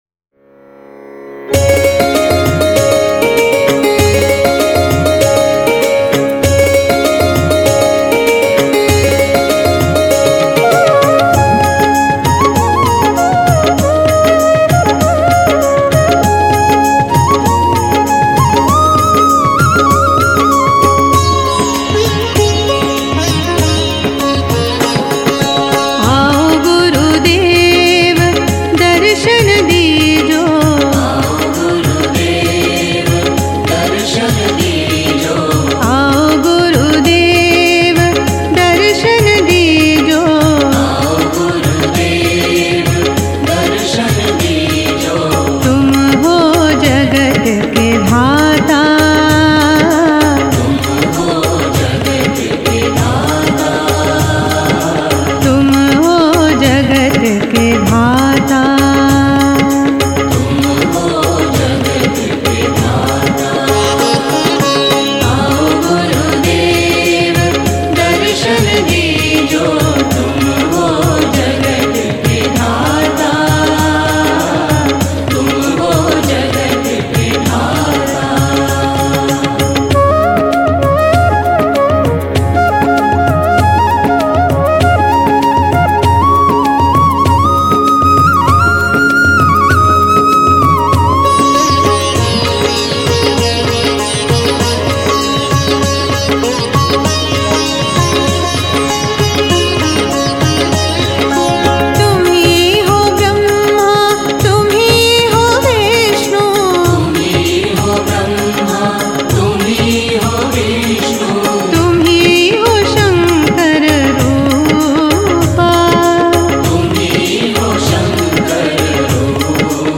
瑜伽唱誦 : Aavo Gurudev